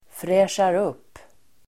Uttal: [²frä:sjar'up:]